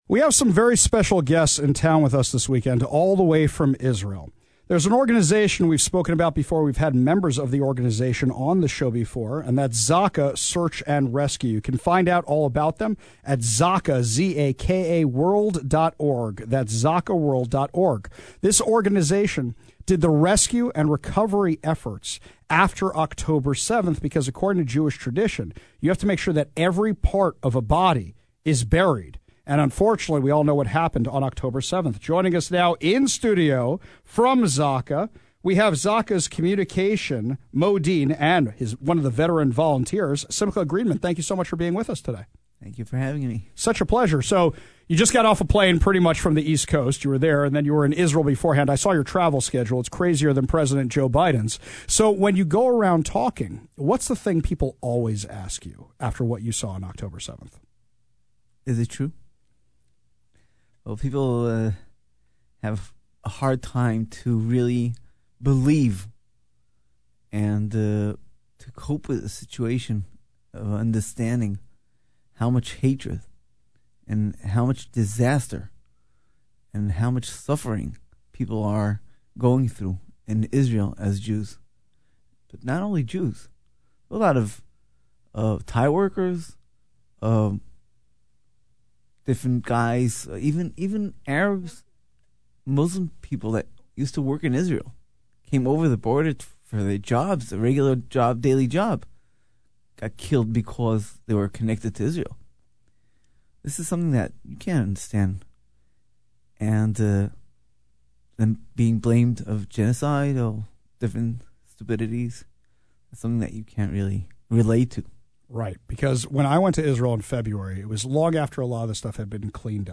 INTERVIEW: Heroes of ZAKA | 570 KVI